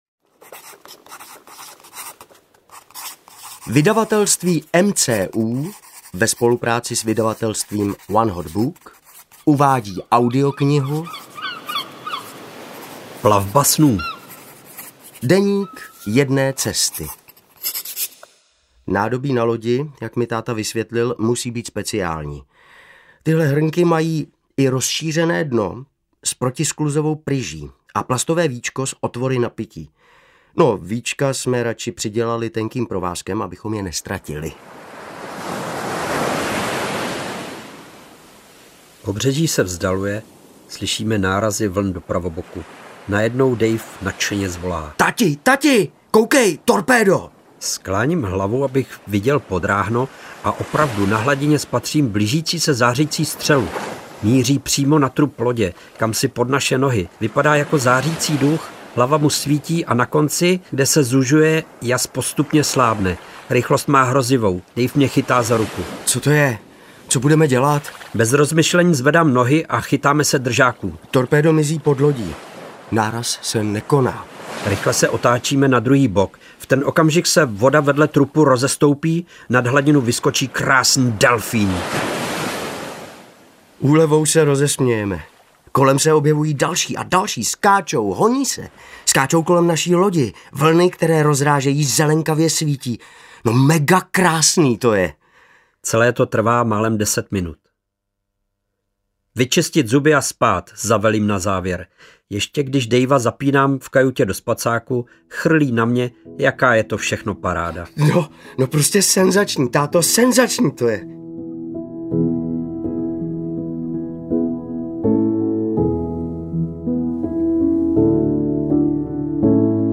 Plavba snů audiokniha
Ukázka z knihy